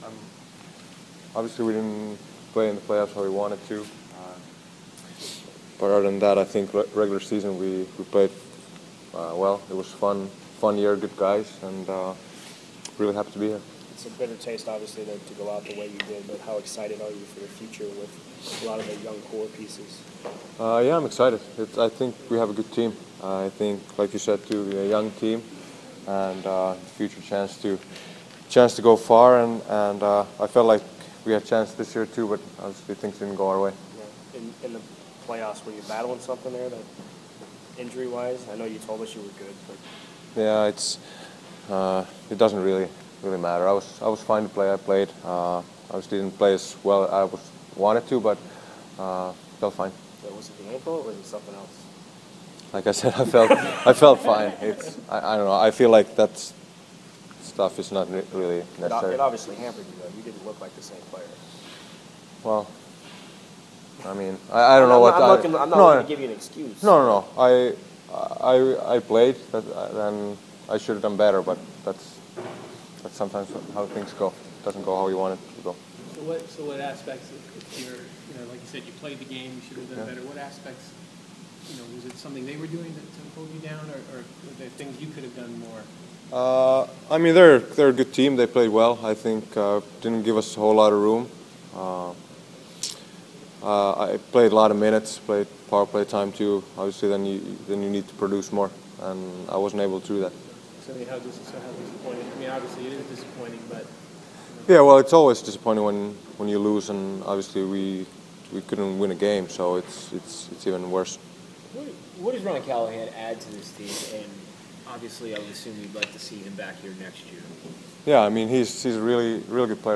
Filppula Exit Interview